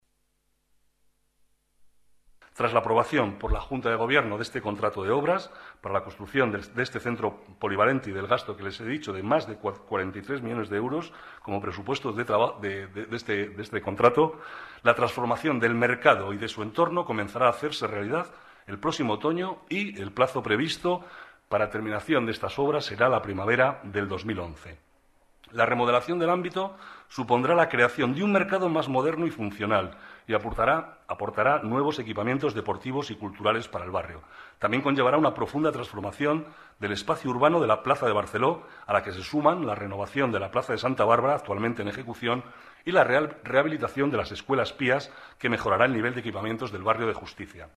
Nueva ventana:Declaraciones del vicealcalde, Manuel Cobo